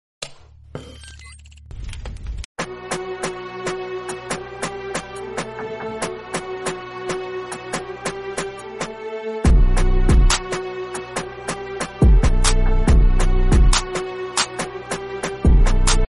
Instrument - Popu Piano